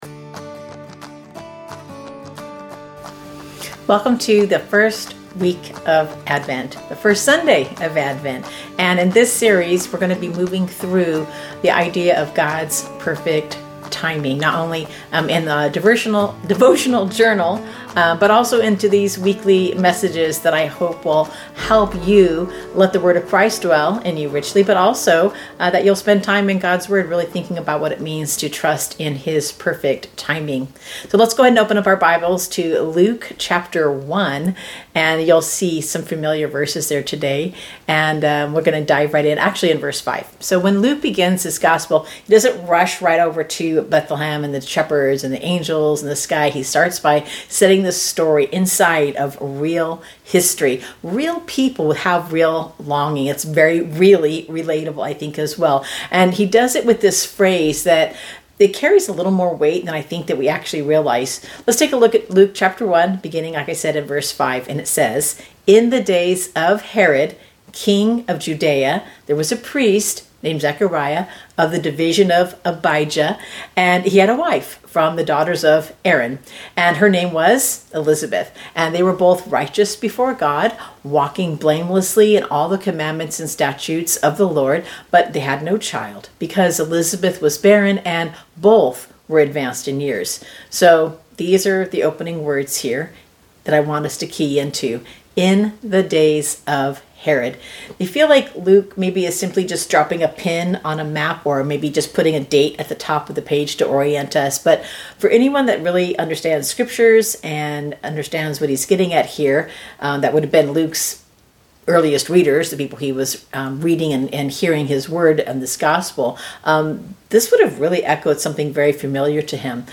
Gods-Perfect-Timing-Advent-Sermon-Week-1.mp3